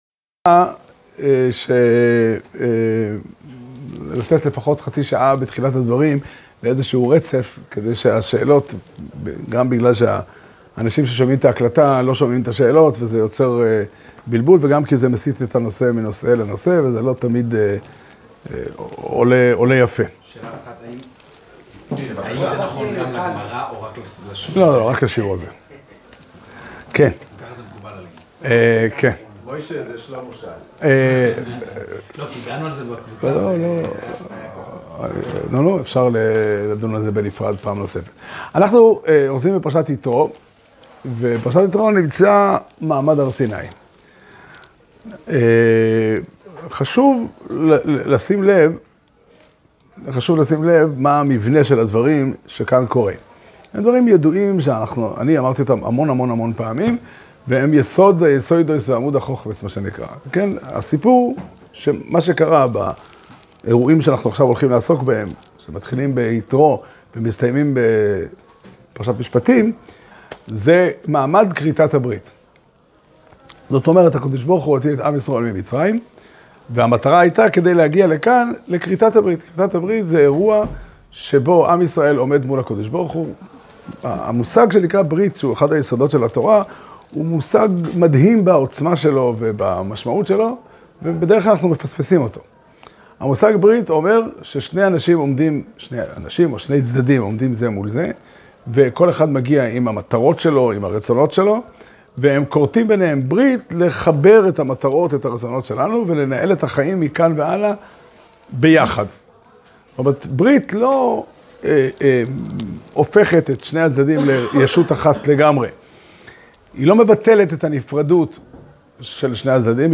שיעור שנמסר בבית המדרש פתחי עולם בתאריך י"ב שבט תשפ"ה